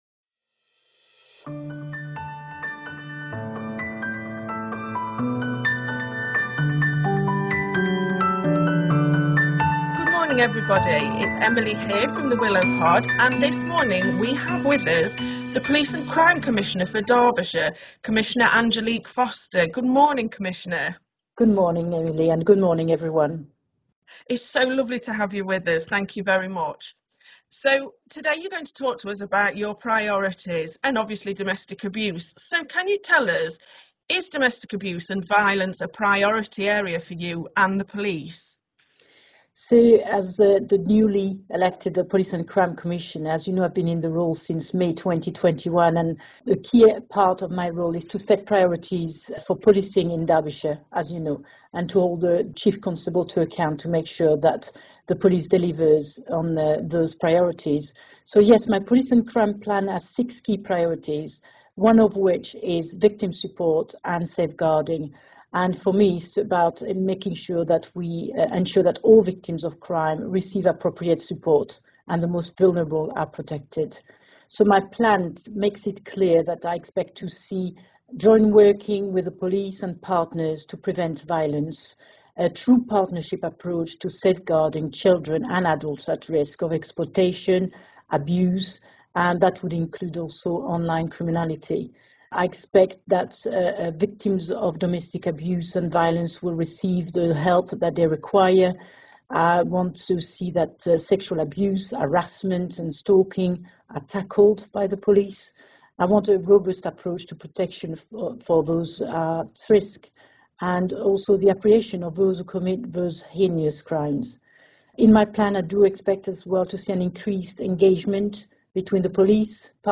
An interview with the Police and Crime Commissioner of Derbyshire, Angelique Foster.